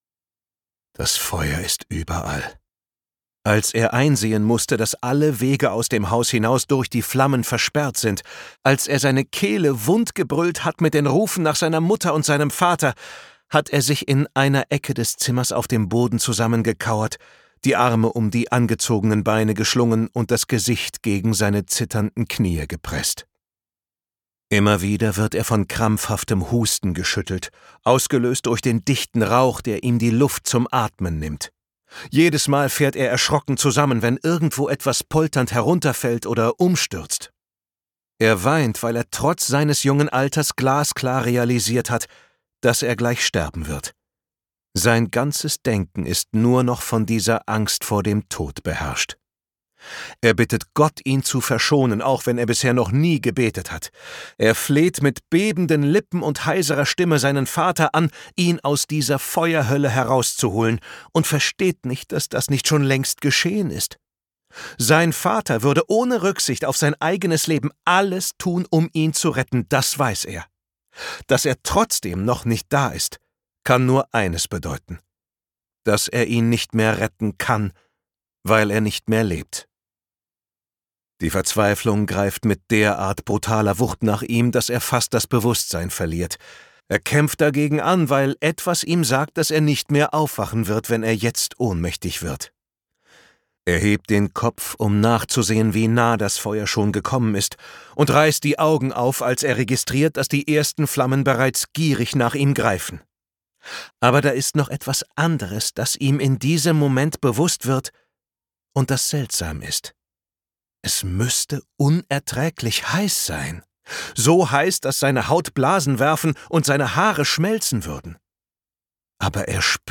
Nervenaufreibender Psycho-Thrill von Nr.1-Bestseller-Autor Arno Strobel.
Gekürzt Autorisierte, d.h. von Autor:innen und / oder Verlagen freigegebene, bearbeitete Fassung.